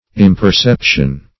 Imperception \Im`per*cep"tion\